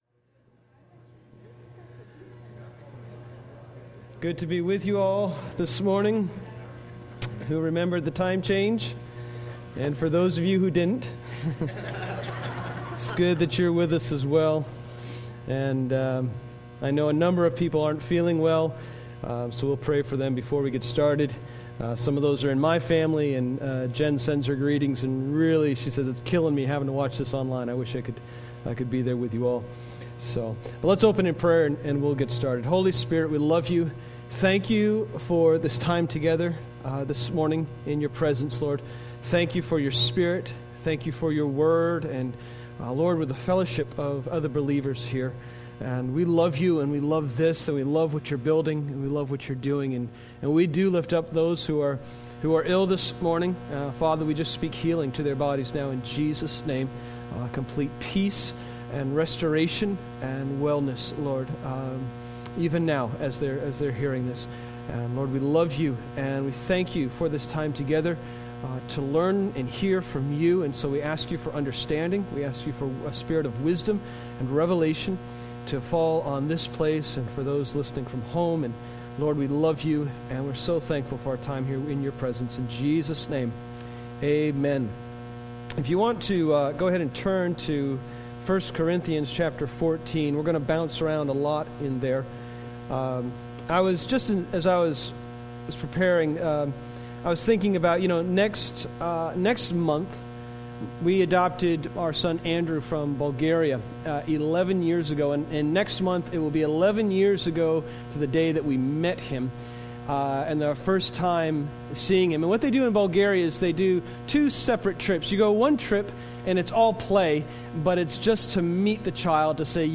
03/14/21